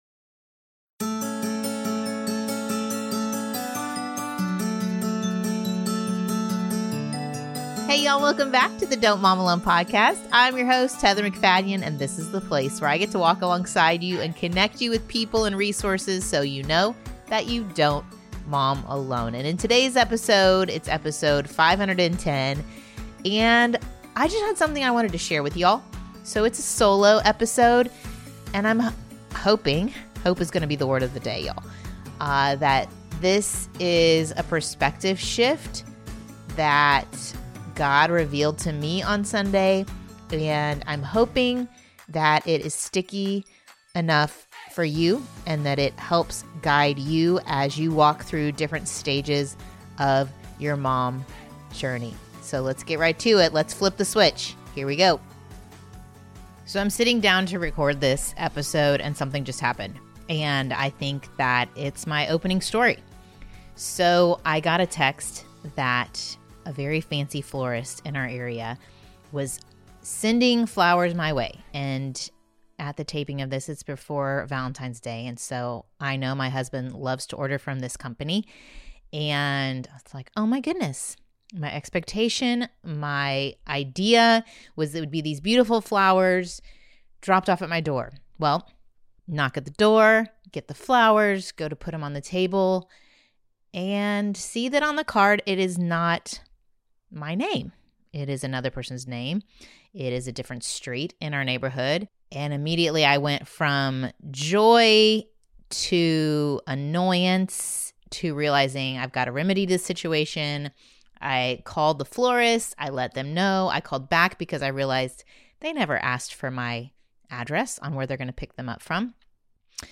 I don’t often do a solo podcast but God has been working on my heart when it comes to parenting teens and expectations and I felt prompted to sit down and share what I am learning with you all.